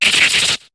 Cri de Séviper dans Pokémon HOME.